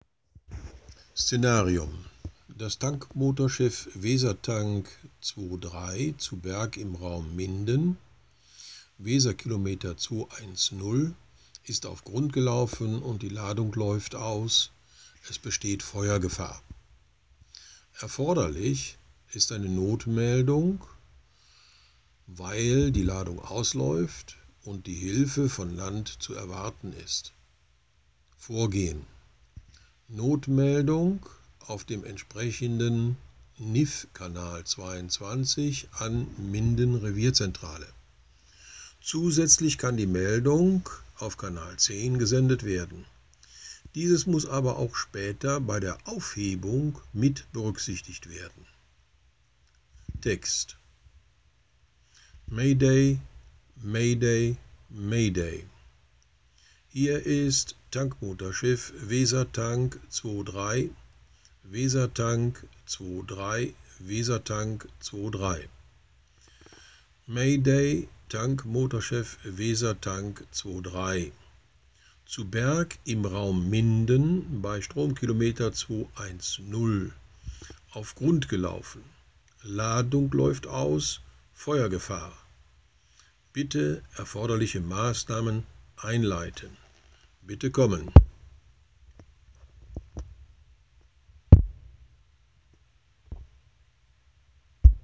UBI - Sprechfunkbeispiele
Vor den eigentlichen Funksprüchen, gleichgültig ob Not-, Dringlichkeits- oder Sicherheitsverkehr, wird das zugehörige Szenarium dargestellt sowie die erforderlichen Maßnahmen und das Vorgehen benannt, um ein größeres Verständniss für den Funkspruch zu erreichen.
2-Wesertank-Mayday.wav